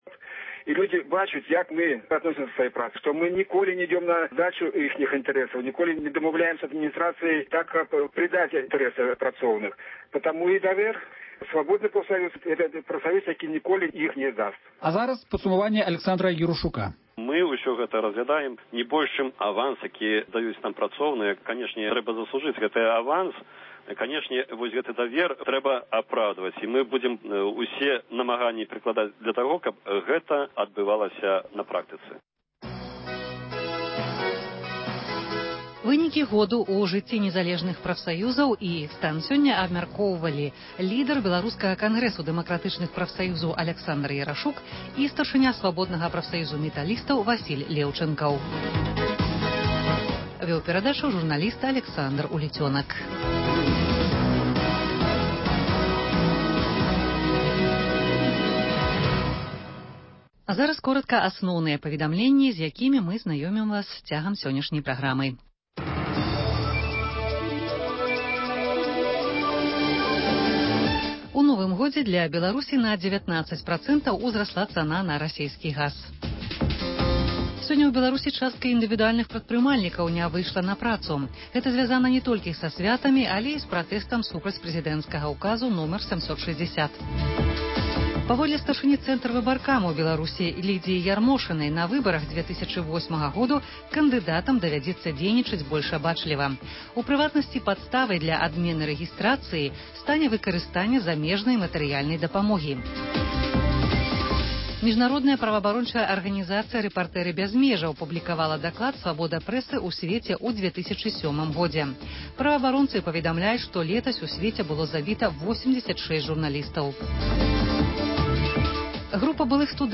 Званкі на “Свабоду”: народ пра Лукашэнку і чыноўнікаў.